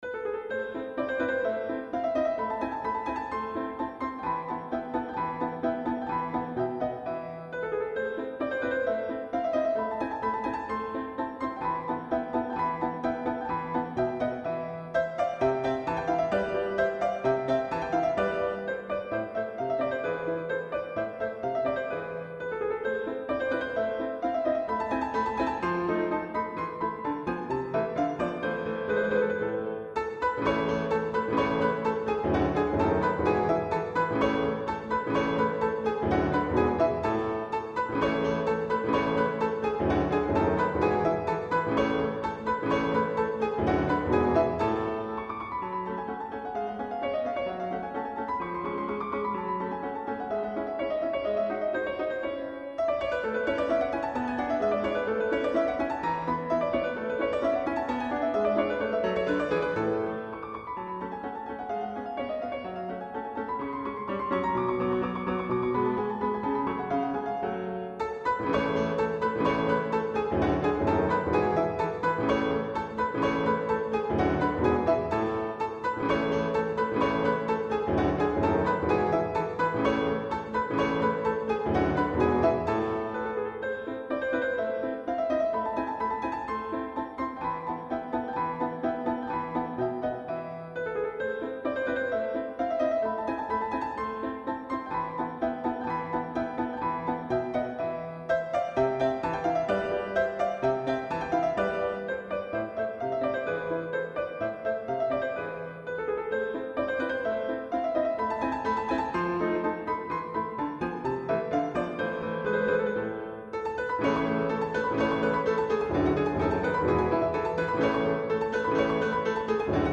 突飛で大胆に見える転調も、シュニットガーならそうせざるを得なかった理由が良く解る。